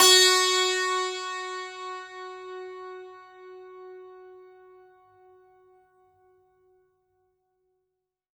52-str07-sant-f#3.wav